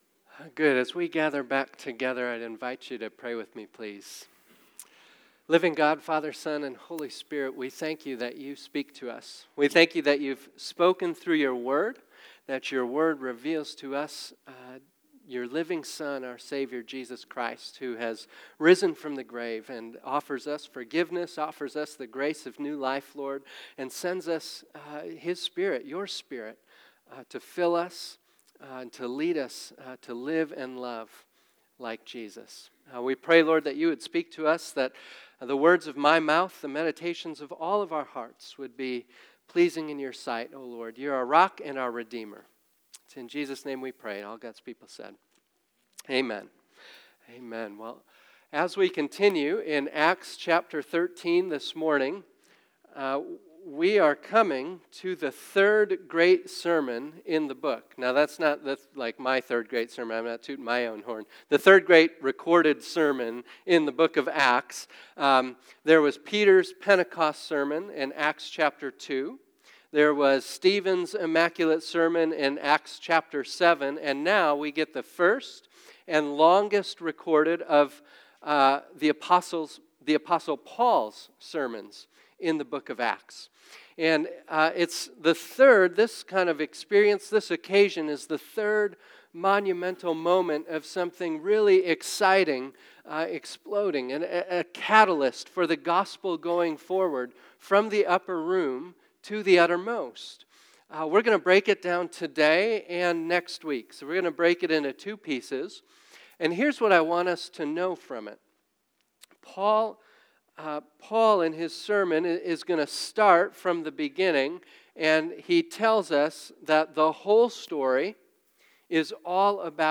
Today we continue our sermon series, Acts: From the Upper Room to the Utter Most.